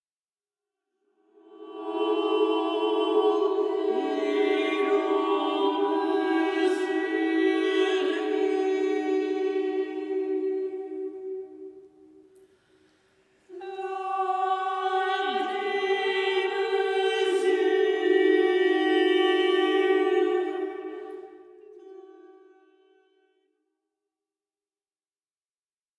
célèbre ensemble vocal
les voix des chanteuses